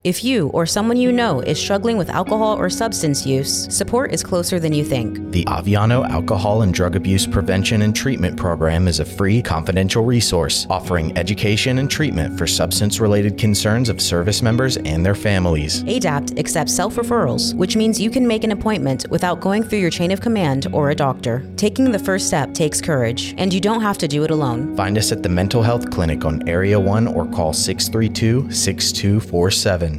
AFN Aviano Radio Spot: ADAPT Program